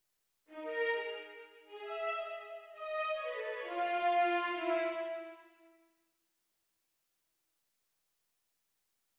Это оркестровое произведение, навеянное сборником поэм Виктора Гюго «Осенние листья» (1831).